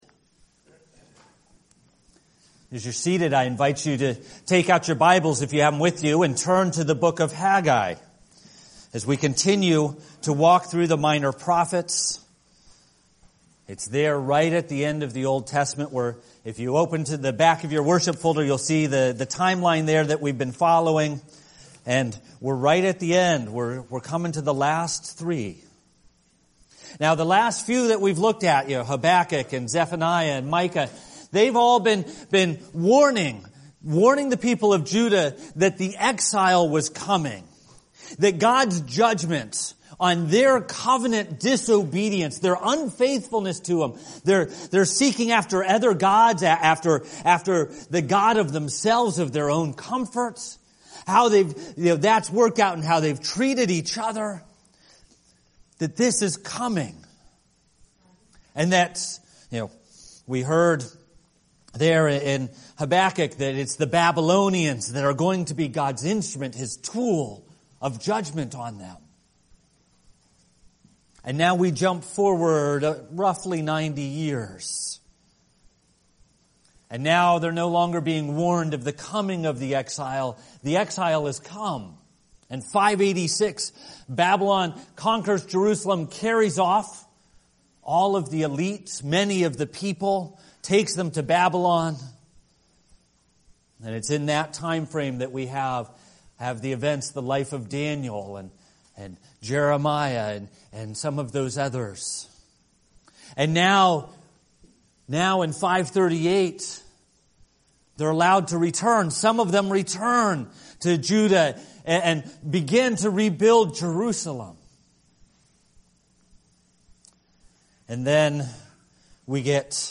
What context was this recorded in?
A Presbyterian Church (PCA) serving Lewiston and Auburn in Central Maine